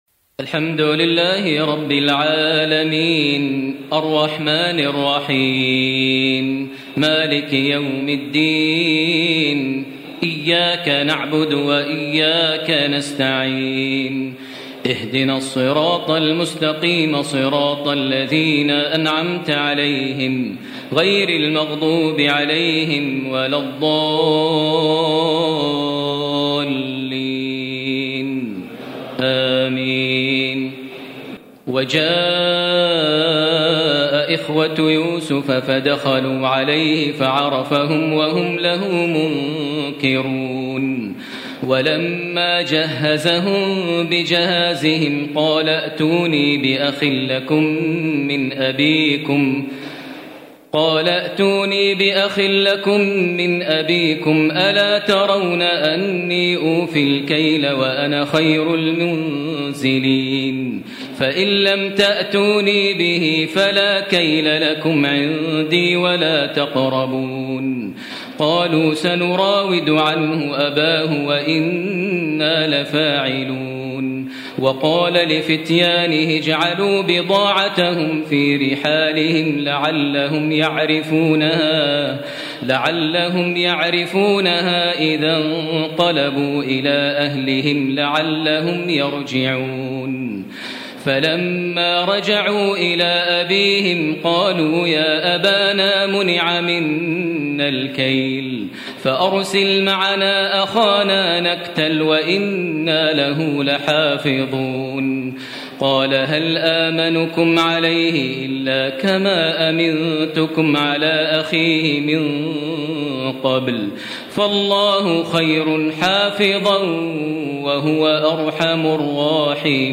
تراويح الليلة الثانية عشر رمضان 1440هـ من سورتي يوسف (58-111) و الرعد (1-18) Taraweeh 12 st night Ramadan 1440H from Surah Yusuf and Ar-Ra'd > تراويح الحرم المكي عام 1440 🕋 > التراويح - تلاوات الحرمين